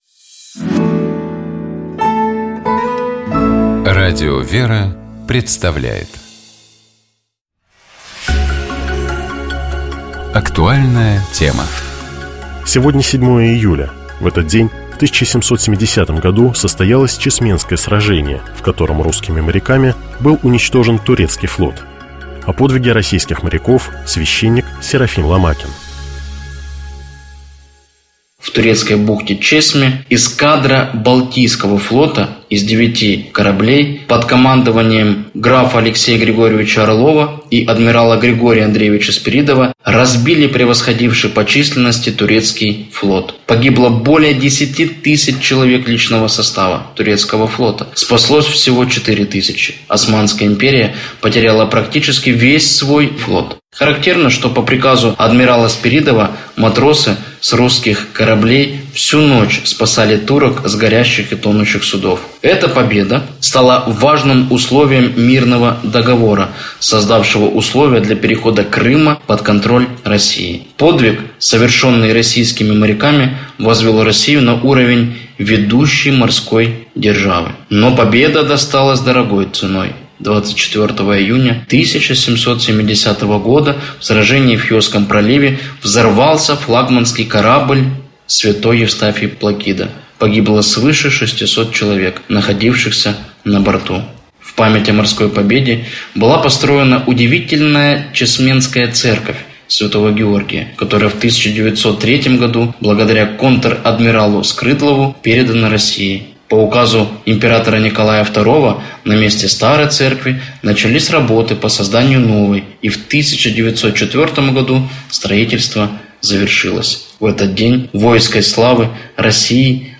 О подвиге российских моряков, — священник